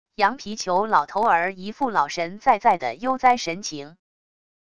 羊皮裘老头儿一副老神在在的悠哉神情wav音频生成系统WAV Audio Player